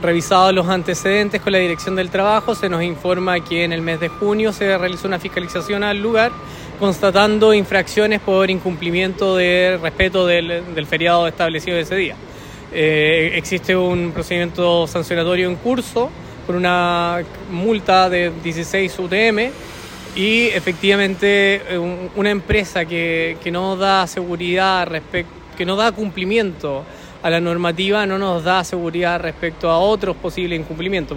Según informó el seremi del Trabajo y Previsión Social, Gonzalo Gutiérrez, hace tres meses la Inspección del Trabajo detectó que además de carecer de autorización, la empresa vulneró derechos laborales básicos de sus empleados.
mall-chino-valdivia-seremi.mp3